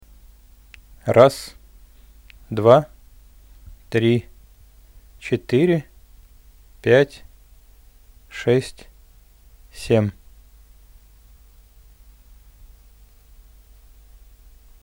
Выход с микшера (Main output) втыкаю в левый канал, баланс кручу до упора влево.